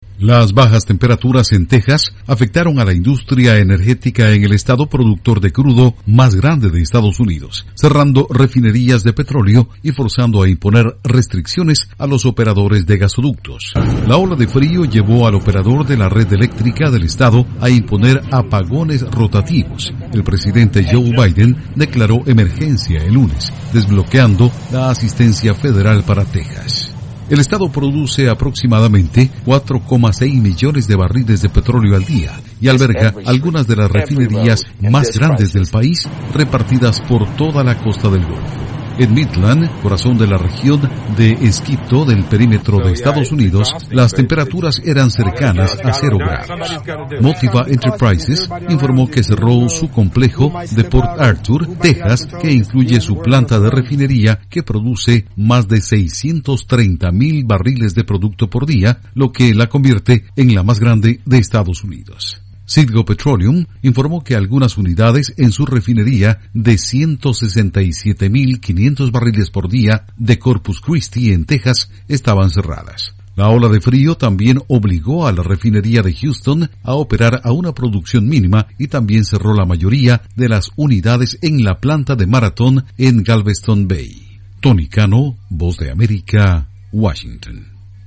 Frío extremo obliga a cerrar refinerías de petróleo de Texas y golpea la industria energética. Informa desde la Voz de América en Washington